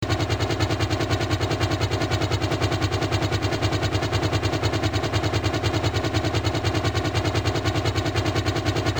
Comproveu que es tracta d'una pista mono amb el so d'un helicòpter. Està parat.